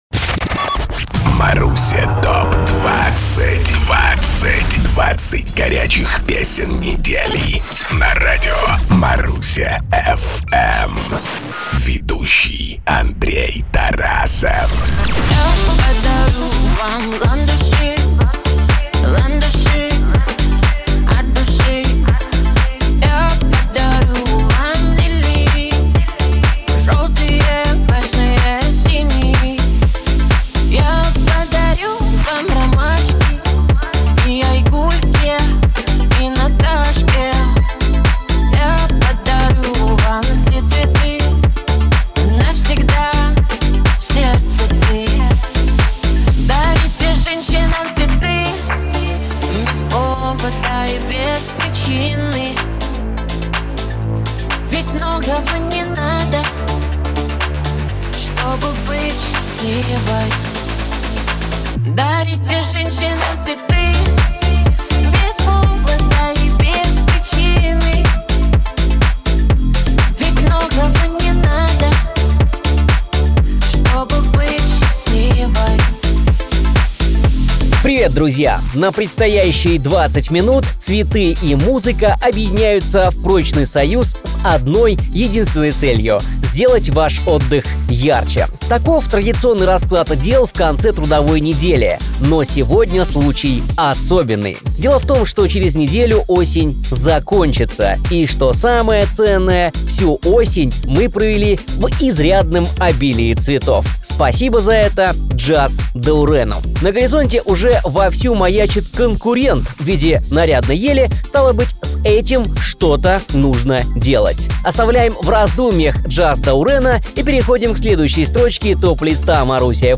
Главные хиты на Марусе! 20 хитов за 20 минут!Ведущий